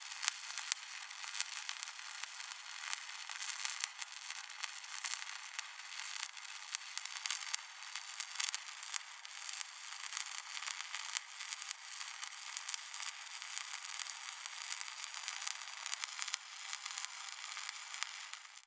welding a piece of metal with sparks flying all over the place
buzz buzzing crackle crackling electric electricity fi fiction sound effect free sound royalty free Sound Effects